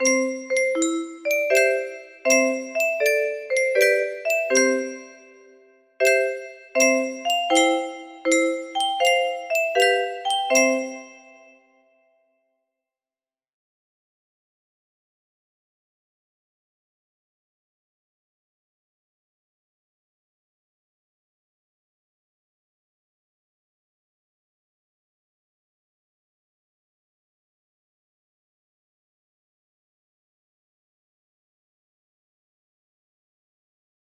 No F4 for Fisher Price Record Pla music box melody